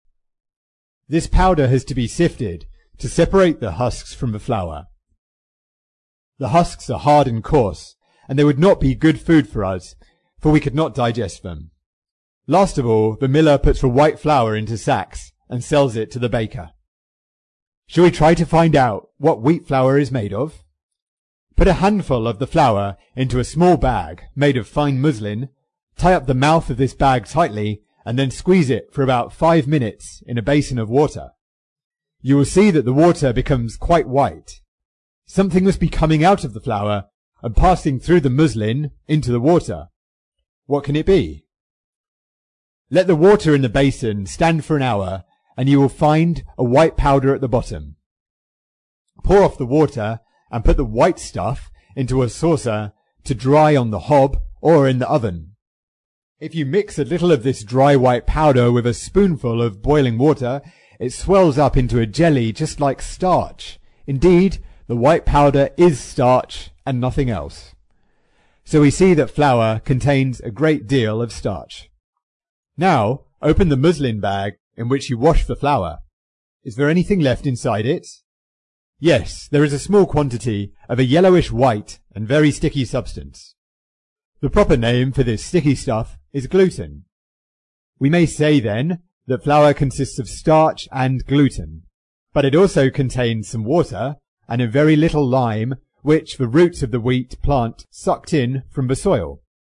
在线英语听力室英国学生科学读本 第95期:面粉与面包(2)的听力文件下载,《英国学生科学读本》讲述大自然中的动物、植物等广博的科学知识，犹如一部万物简史。在线英语听力室提供配套英文朗读与双语字幕，帮助读者全面提升英语阅读水平。